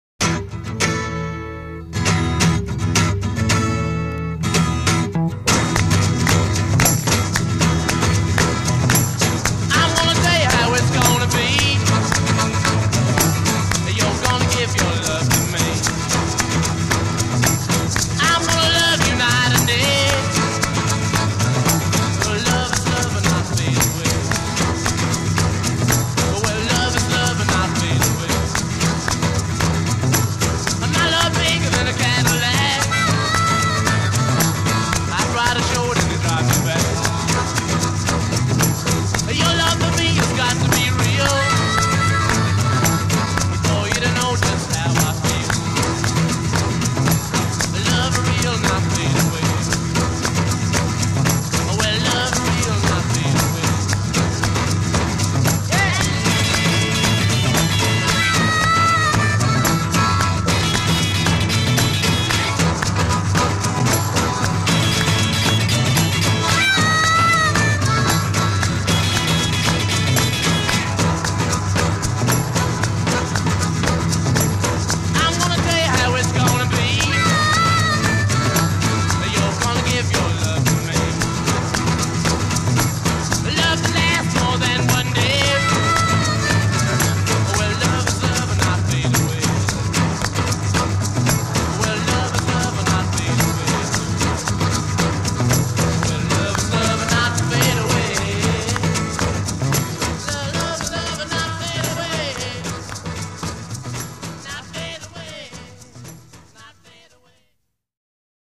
Unidentified maraccas and tambourine.
intro 0:00 3 + 2 guitar chords followed by ensemble
A verse 0: 10 vocal solo alternating with harmonica a
A " : 8 + 1 electric guitar alternates with harmonica solo
coda : 6+ repeat hook and fade (away)